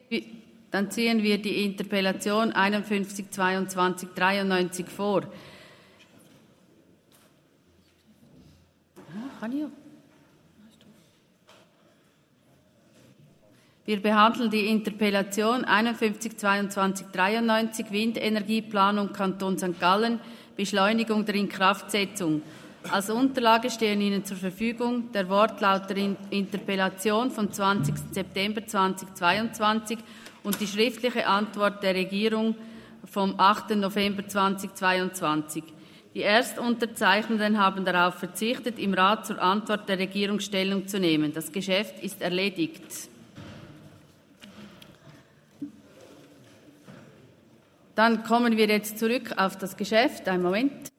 20.9.2023Wortmeldung
Session des Kantonsrates vom 18. bis 20. September 2023, Herbstsession